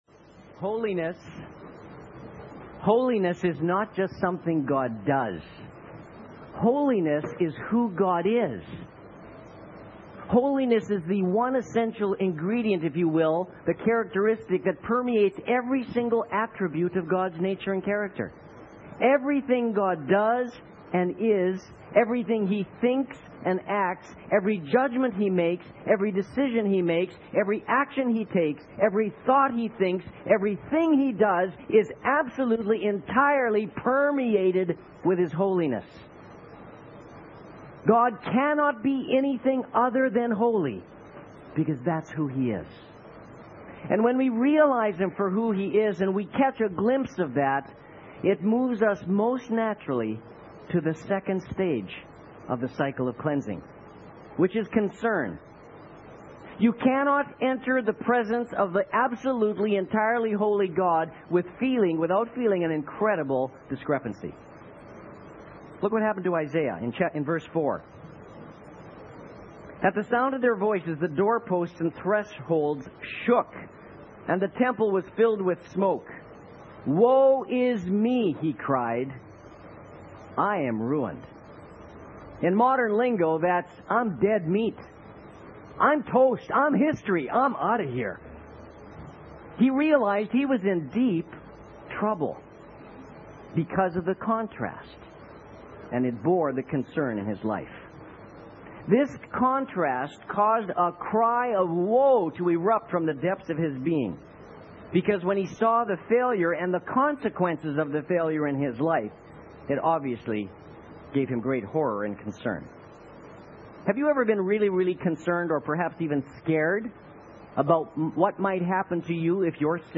*Note: The exact date for this sermon is unknown.